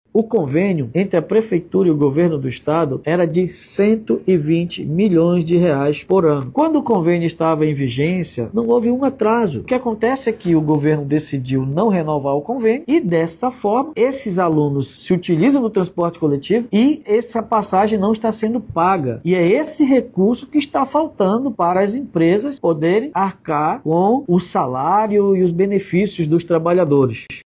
Por meio das redes sociais, o prefeito de Manaus, Davi Almeida, disse que os atrasos salariais dos rodoviários é consequência do rompimento do convênio do Passe Livre Estudantil.